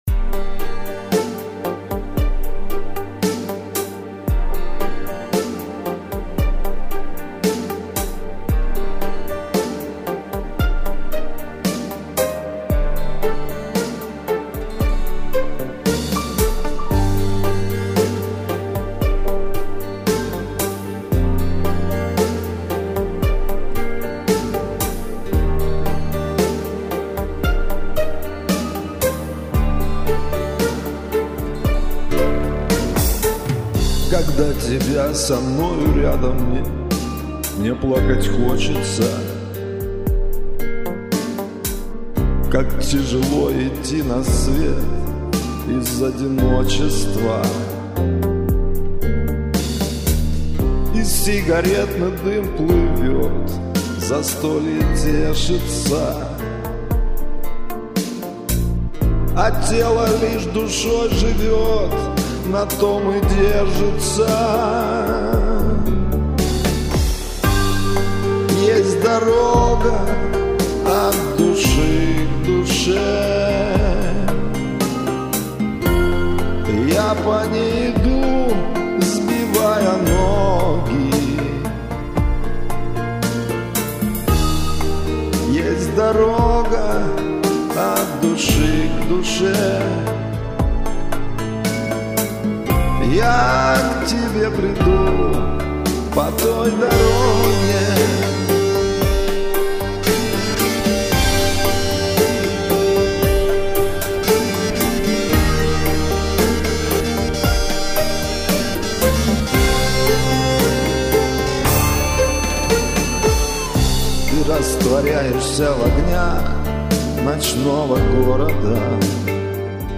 Чистенько!